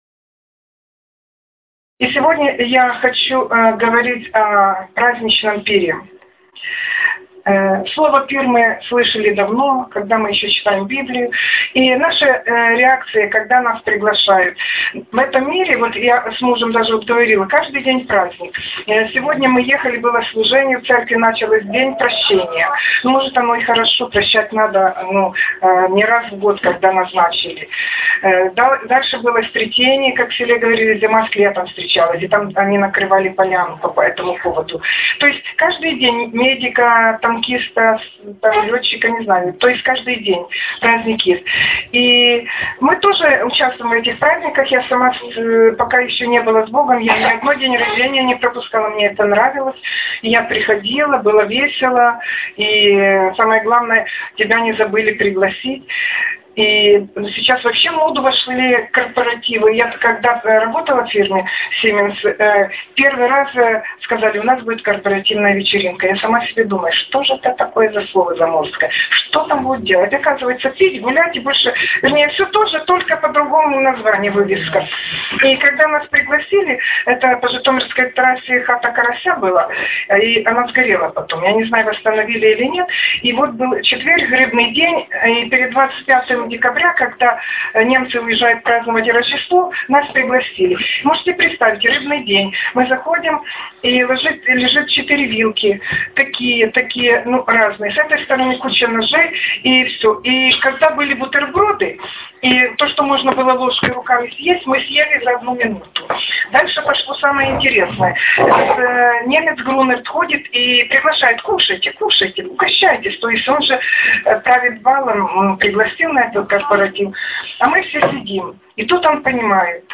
Актуальная проповедь